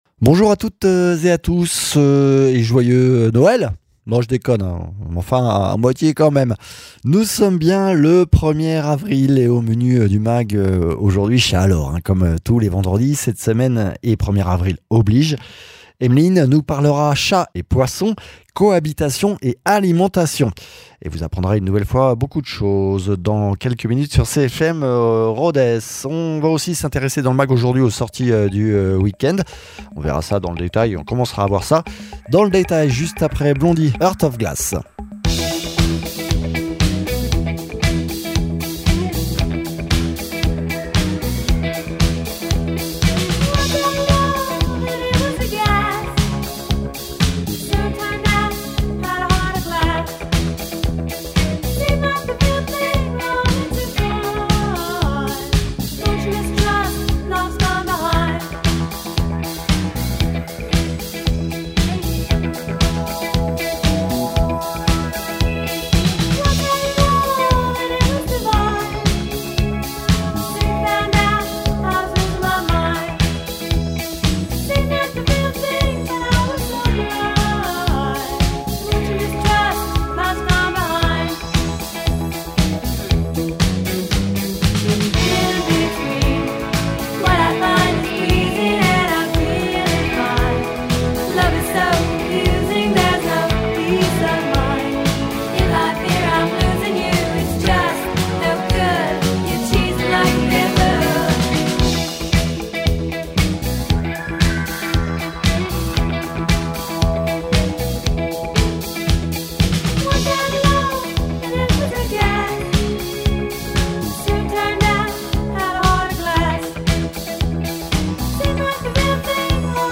comportementaliste félin